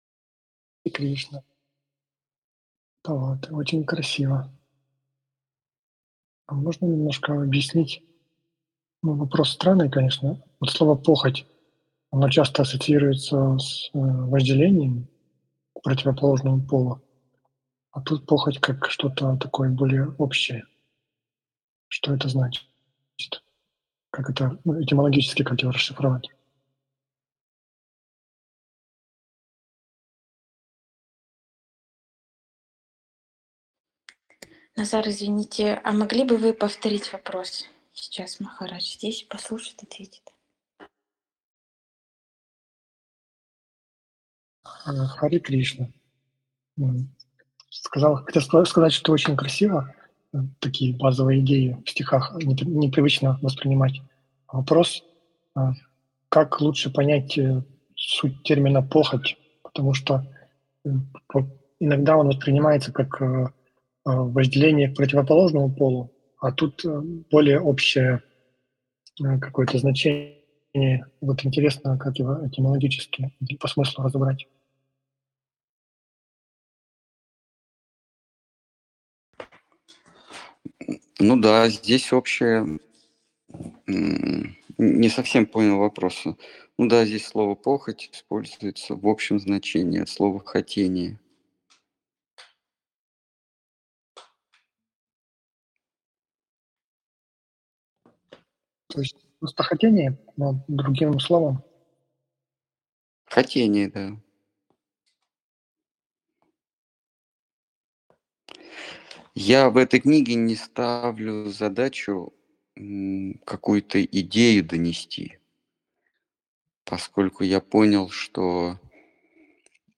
Ответы на вопросы из трансляции в телеграм канале «Колесница Джаганнатха». Тема трансляции: Пилотная версия Гиты.